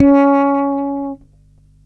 39-C#4.wav